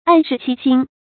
暗室欺心 àn shì qī xīn 成语解释 在黑暗的屋子里昧着良心做坏事。